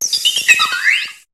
Cri de Fragilady dans Pokémon HOME.